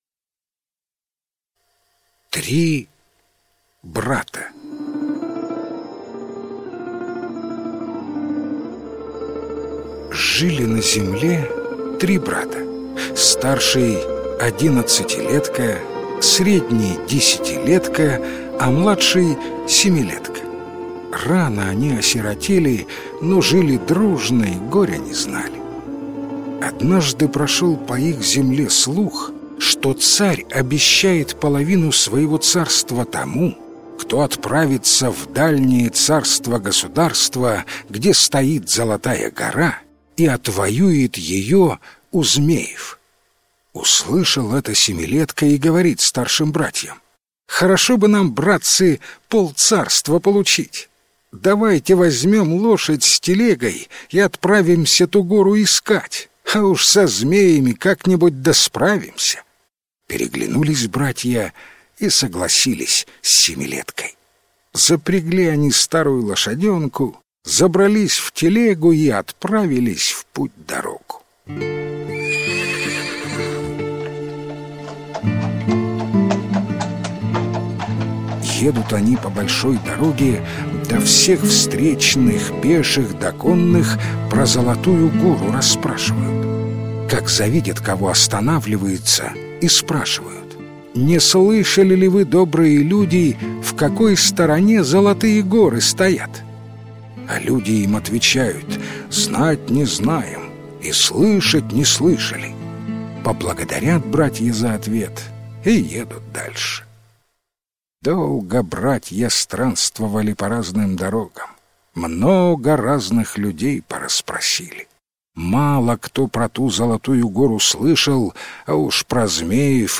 Три брата - украинская аудиосказка - слушать онлайн